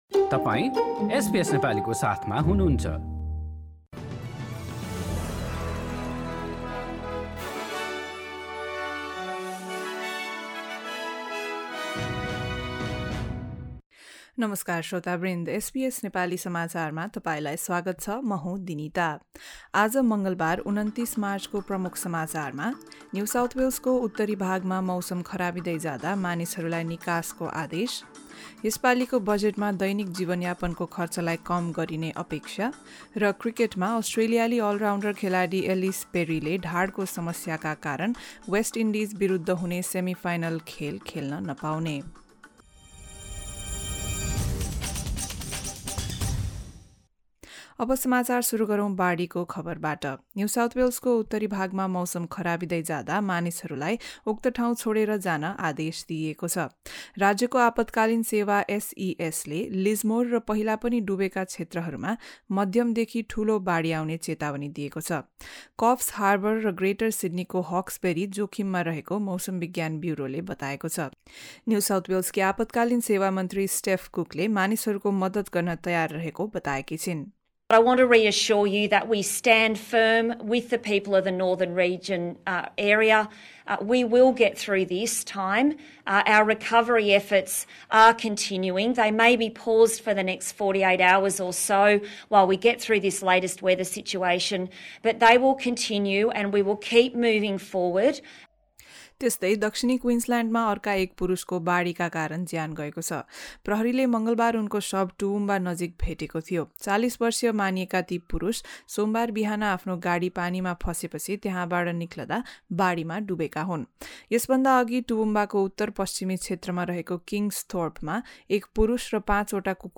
एसबीएस नेपाली अस्ट्रेलिया समाचार: मङ्गलबार २९ मार्च २०२२